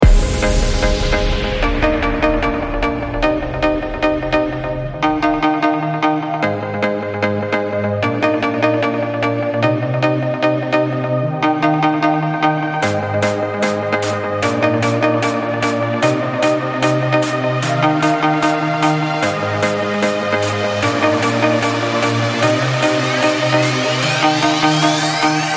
Танцевальные
Оч приятно звучит!)))
Метки: веселые, мелодичные, Hard Trance,